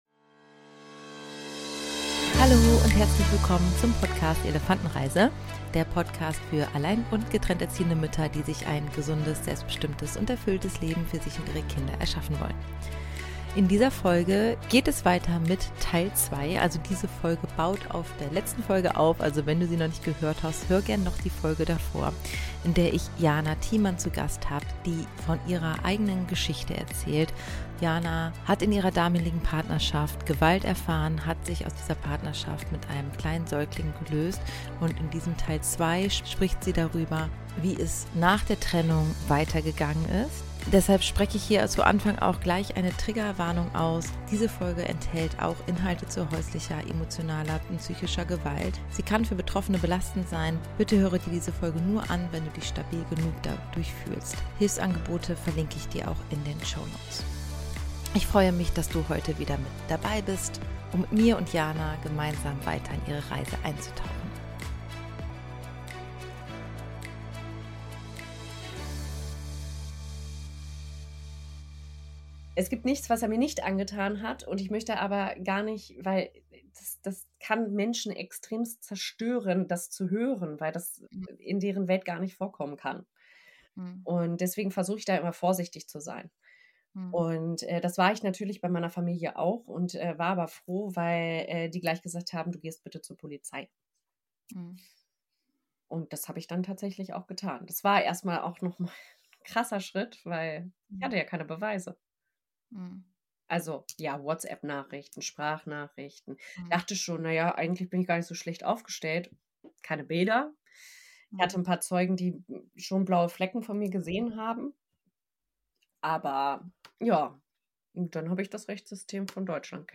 In Teil 2 dieses Gesprächs berichtet die Betroffene davon, was nach der Trennung passiert ist. Sie erzählt, wie sie den Schritt zur Polizei gegangen ist, welche Hürden, Ängste und Unsicherheiten damit verbunden waren – und wie sich dieser Moment trotzdem als wichtiger Wendepunkt erwiesen h...